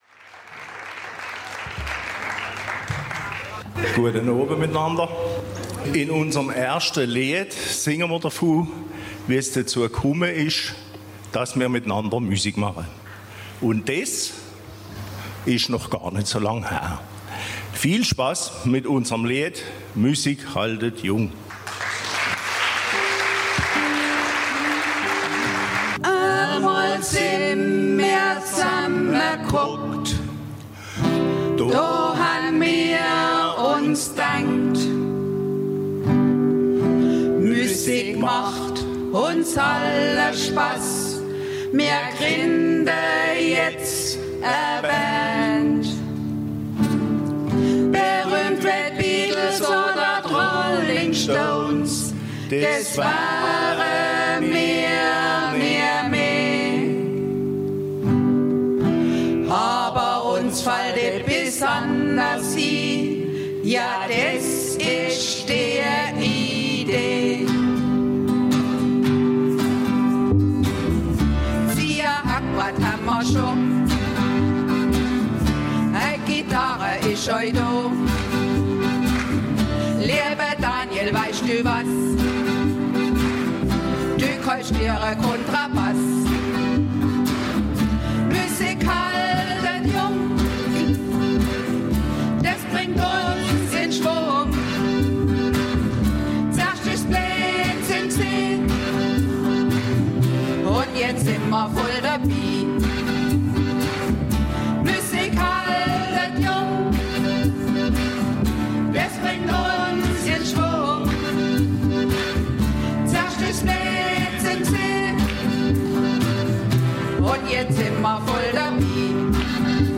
Landesgartenschau Neuenburg
LGS_Neuenburg_Ausschnitt-Mundartnacht.ogg